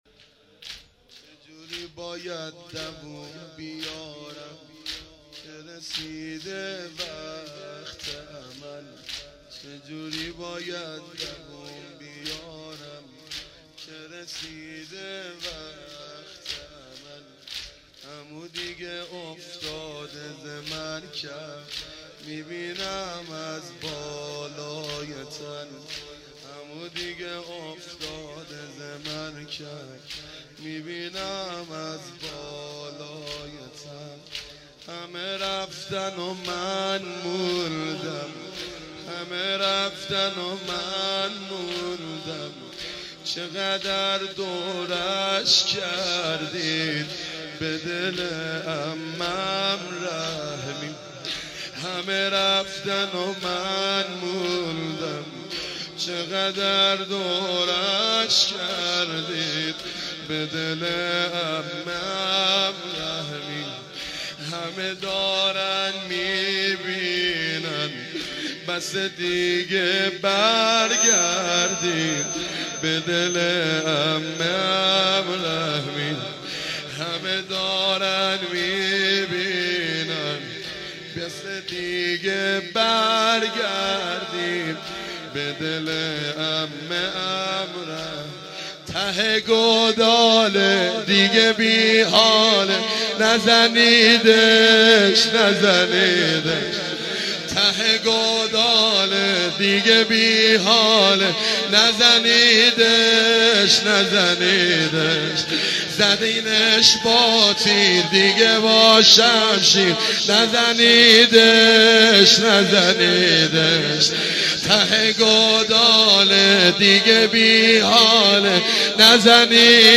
شب 6 محرم95
چه جوری باید دووم بیارم که رسیده (زمینه جدید)